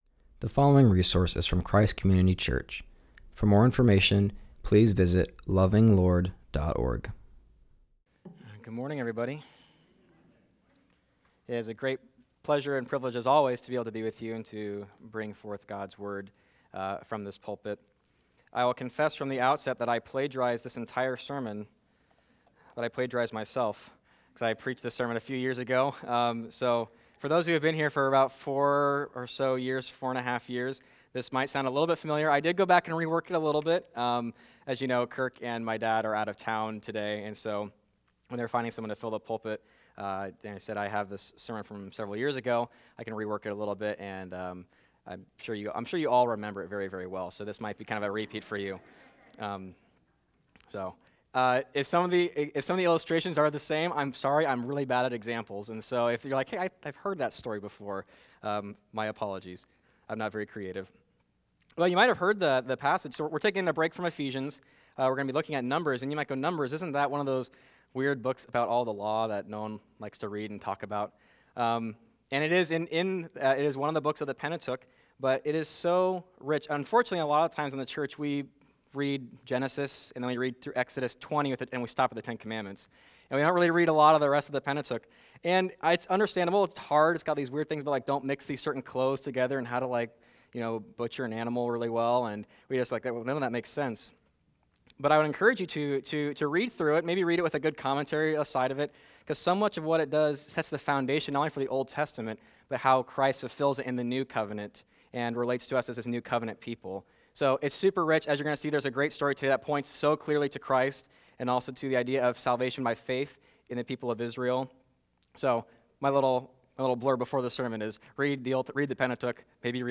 will be preaching from Numbers 21:4-9.